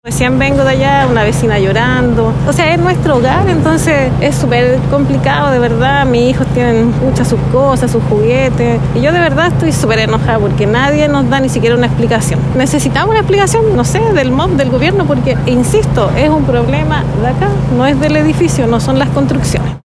vecina-evacuacion-edificio.mp3